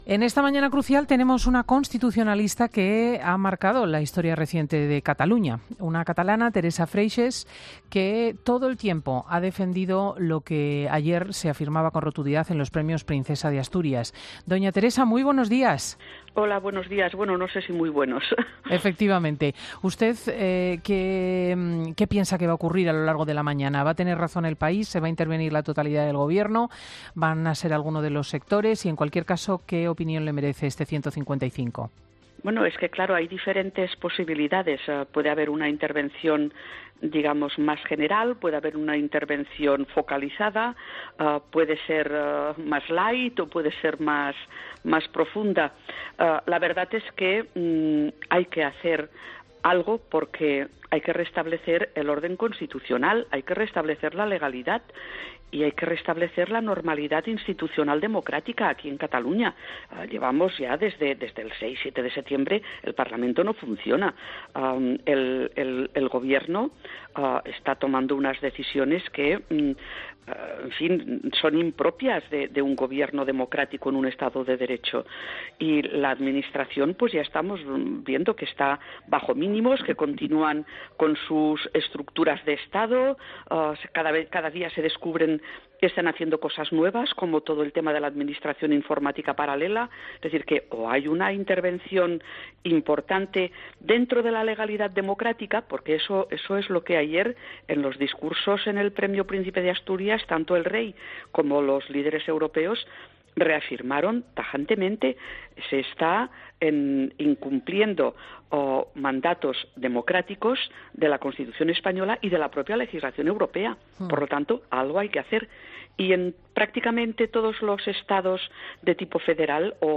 José Manuel Barreiro (Portavoz del PP en el Senado) ha pasado por los micrófonos hoy de Fin de Semana y ha afirmado obre el proceso que queda por...